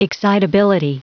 Prononciation du mot excitability en anglais (fichier audio)
Prononciation du mot : excitability